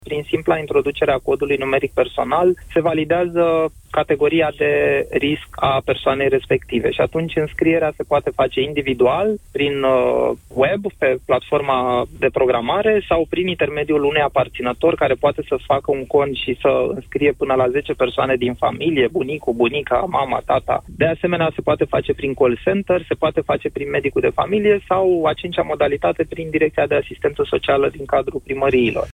Persoanele peste 65 de ani se vor putea programa şi singure, a spus Valeriu Gheorghiţă, în emisiunea Deşteptarea: